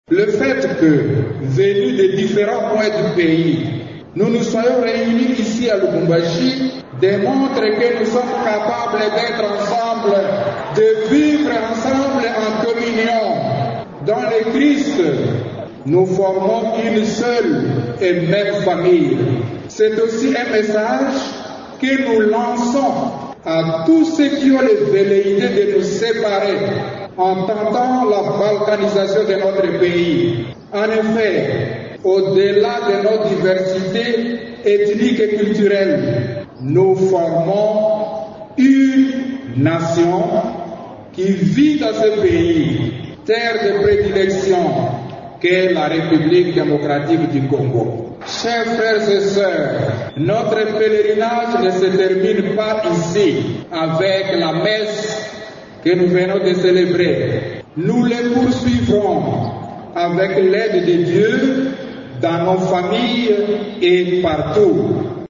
La messe a été célébrée au stade TP Mazembe de la commune de Kamalondo, par l’envoyé spécial du pape, le cardinal Luis Antonio Tagle, propréfet du Dicastère pour l’Evangélisation.
Le président de la CENCO, Mgr Marcel Utembi, a pour sa part condamné toute division et toute balkanisation  du pays en confessant l’unité nationale: